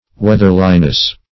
weatherliness - definition of weatherliness - synonyms, pronunciation, spelling from Free Dictionary
Weatherliness \Weath"er*li*ness\, n. (Naut.)